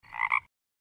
Звуки жабы